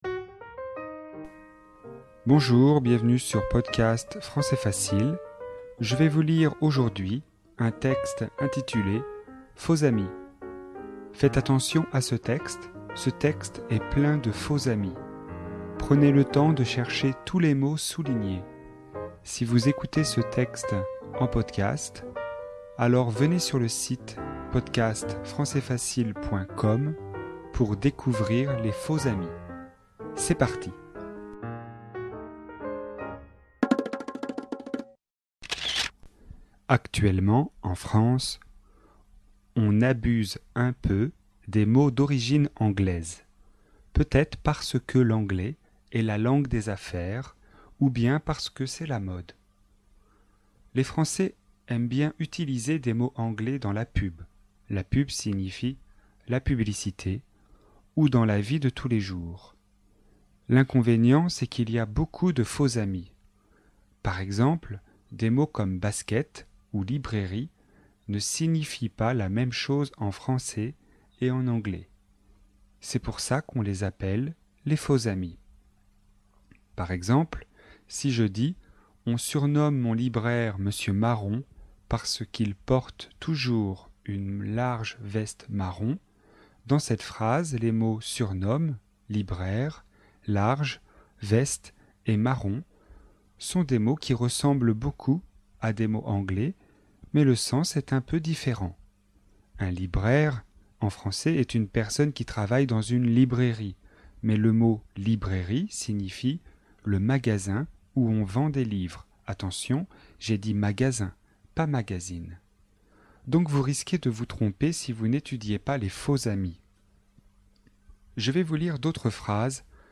Leçon de vocabulaire, niveau intermédiaire (B1), sur le thème des faux amis.